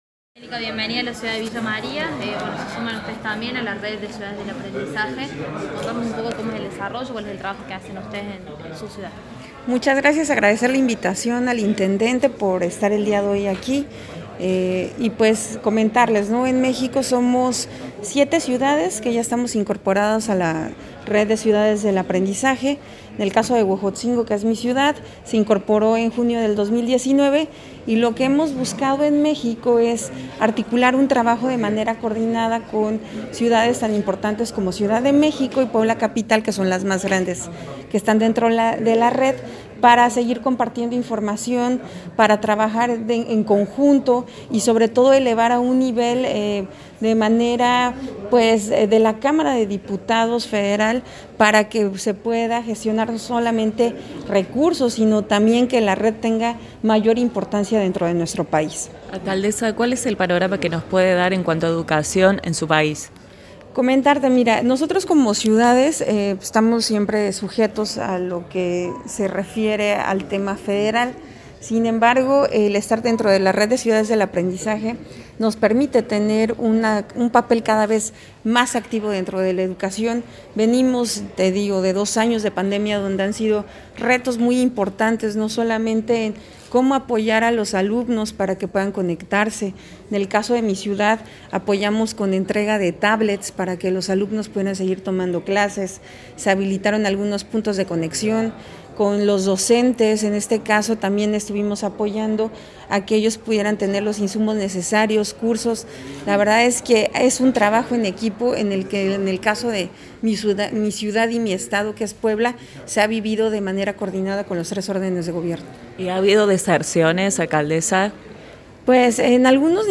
La alcaldesa mexicana Angélica Alvarado que participa del Encuentro de Ciudades del Aprendizaje que se realiza en nuestra ciudad, trazó un balance de estas jornadas donde ponderó la participación de Villa María en la red de las ciudades del aprendizaje.